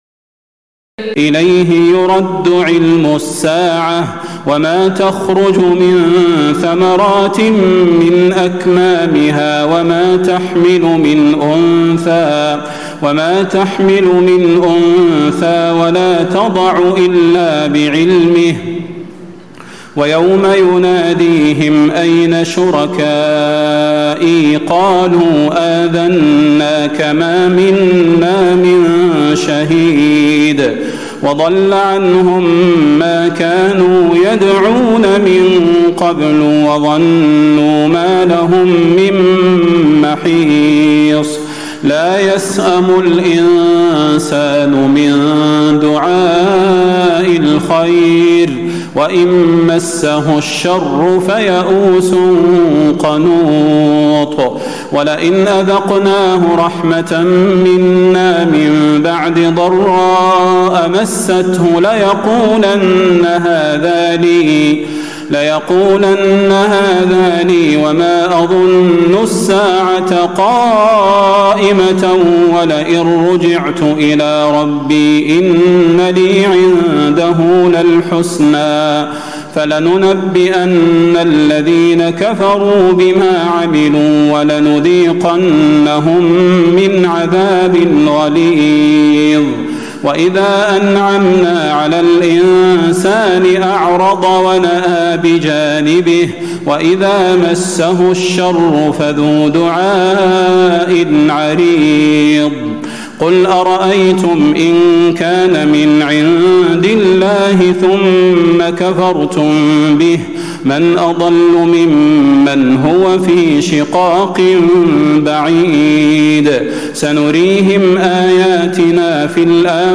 تراويح ليلة 24 رمضان 1436هـ من سور فصلت (47-54) و الشورى و الزخرف (1-25) Taraweeh 24 st night Ramadan 1436H from Surah Fussilat and Ash-Shura and Az-Zukhruf > تراويح الحرم النبوي عام 1436 🕌 > التراويح - تلاوات الحرمين